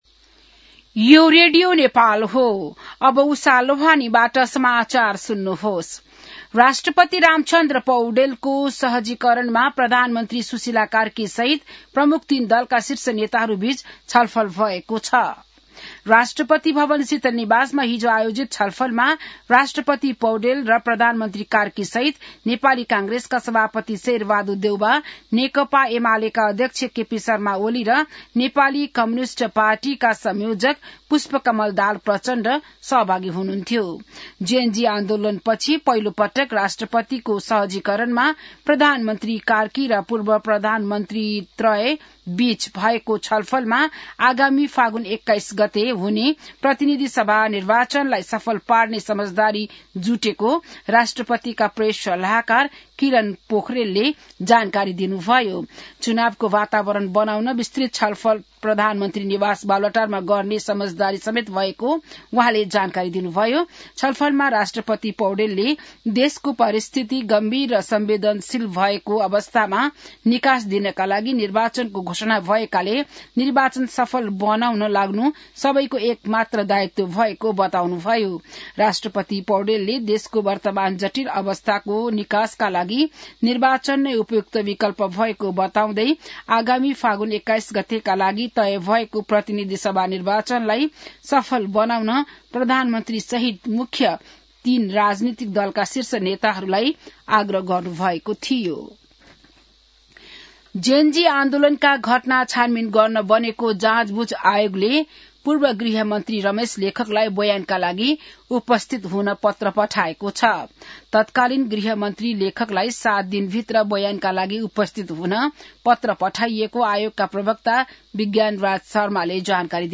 बिहान १० बजेको नेपाली समाचार : ९ पुष , २०८२